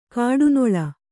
♪ kāḍu noḷa